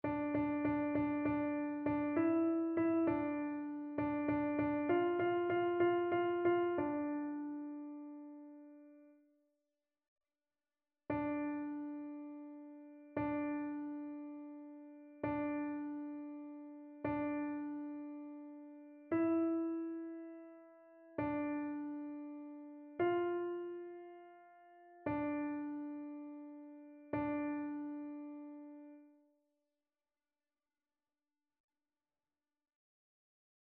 annee-abc-fetes-et-solennites-assomption-de-la-vierge-marie-psaume-44-alto.mp3